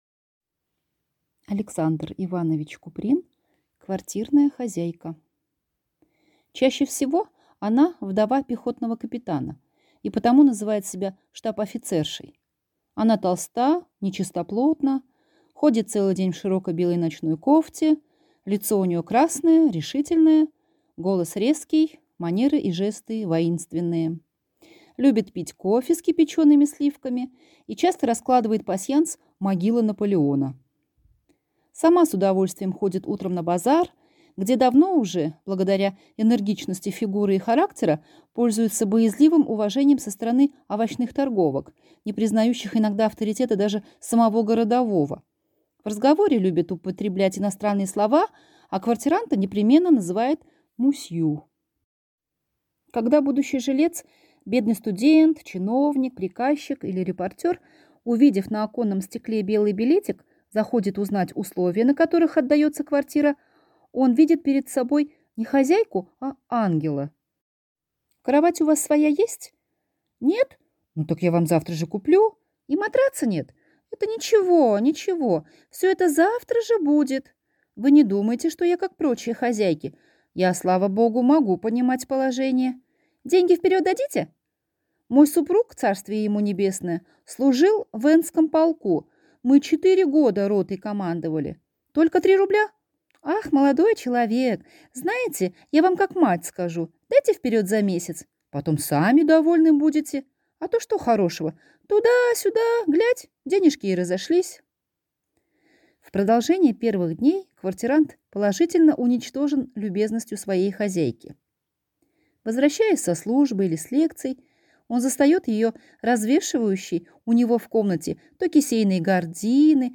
Аудиокнига Квартирная хозяйка | Библиотека аудиокниг